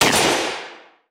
shotgun_6.wav